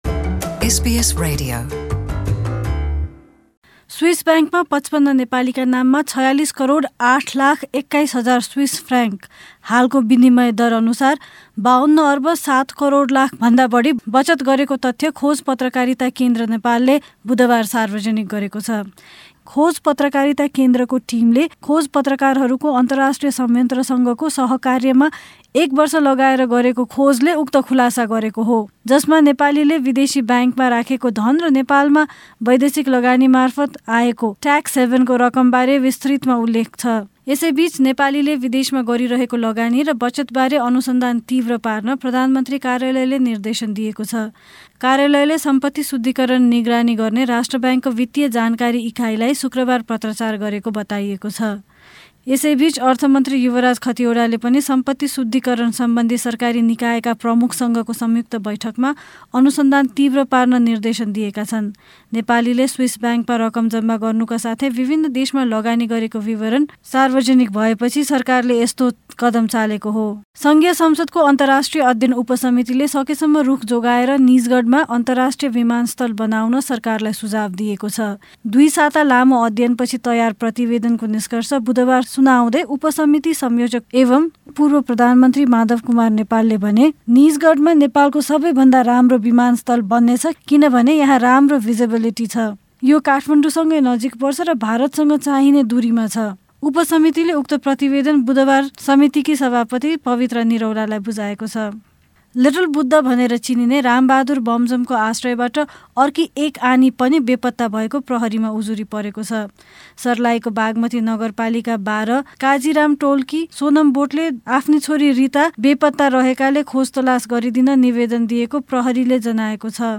गत ७ दिनका नेपालका केहि मुख्य समाचार।